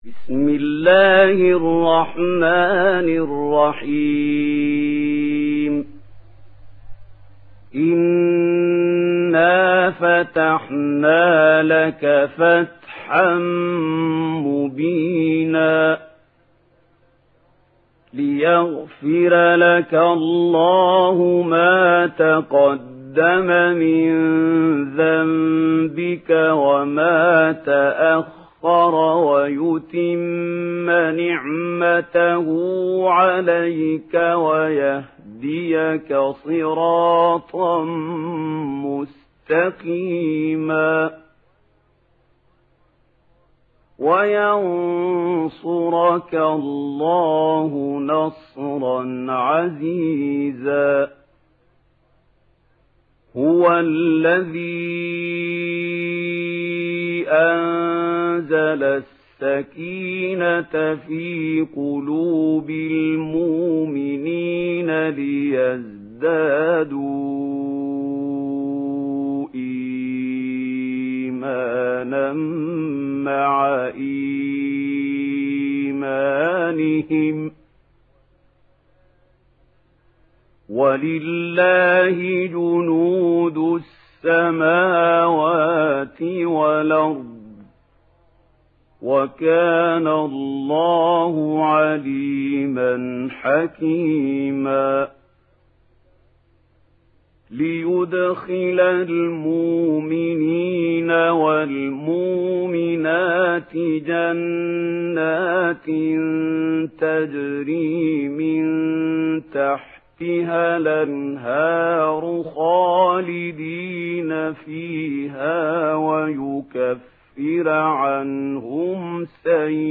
Surah Al Fath Download mp3 Mahmoud Khalil Al Hussary Riwayat Warsh from Nafi, Download Quran and listen mp3 full direct links